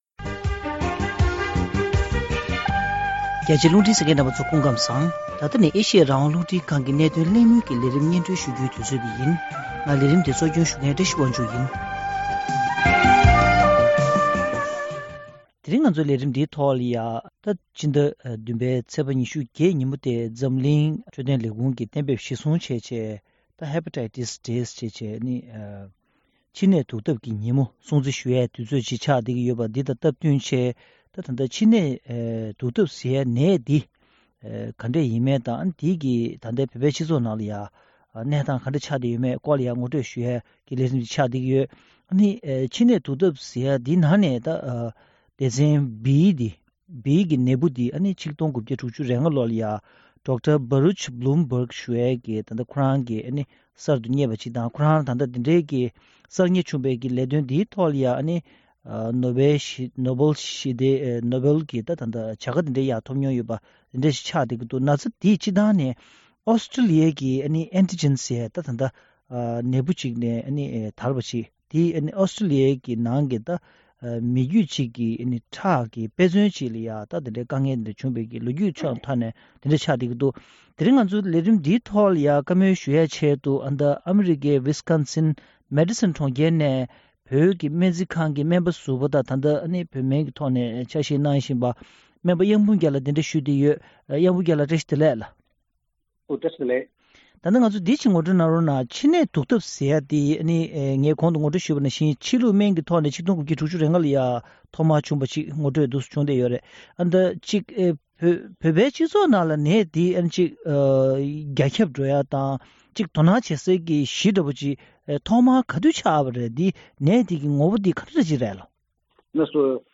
མཆིན་ནད་དུག་ཐབས་སམ་མཆིན་པའི་གཉན་ཚད་ཀྱི་ངོ་བོ་དང་བཙན་བྱོལ་བོད་མིའི་ཁྲོད་ཀྱི་ཁྱབ་བརྡལ་ཚབས་ཆེ་ཇི་ཡོད་སོགས་ཀྱི་སྐོར་སྨན་པ་ཁག་དང་ལྷན་གླེང་མོལ་ཞུས་པ།